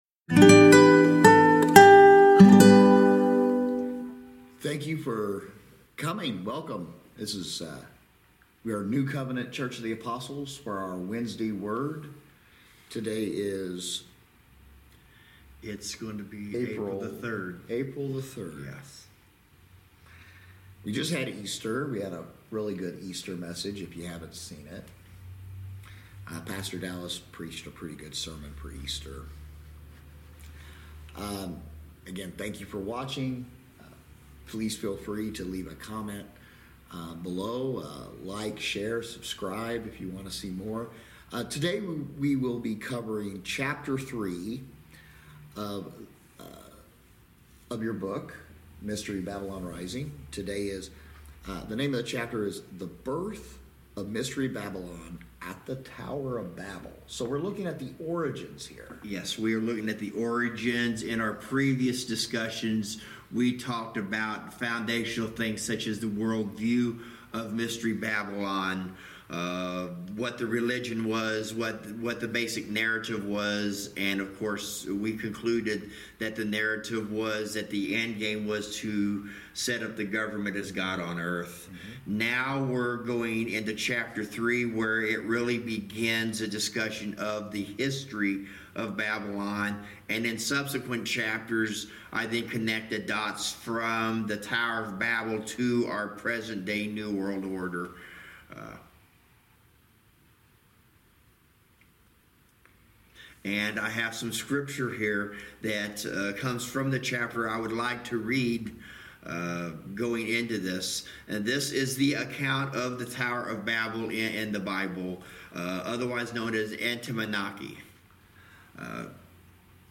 Wednesday Word Bible Study